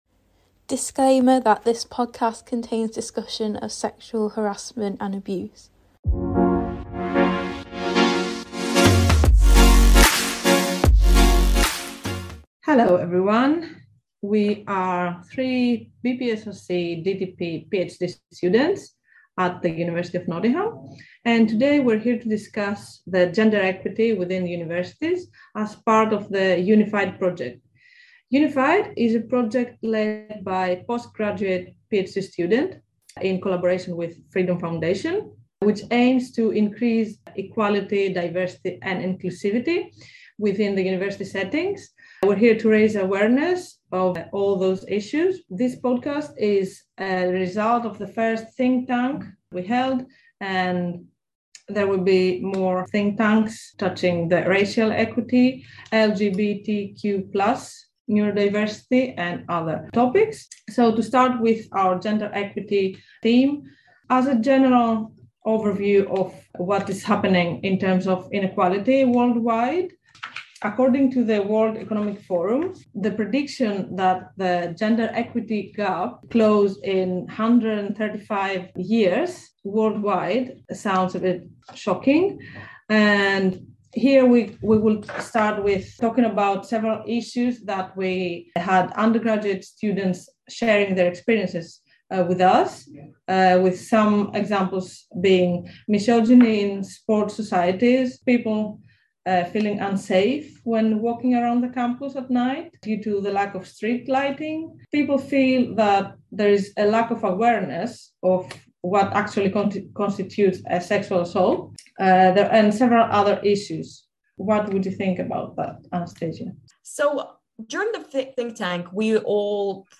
Facebook Twitter Headliner Embed Embed Code See more options *Disclaimer that this podcast contains discussion of topics related to sexual harassment and sexual abuse* Join postgraduate UoN students as they discuss their experiences in the university setting, starting with the topic of gender equity and gender equality.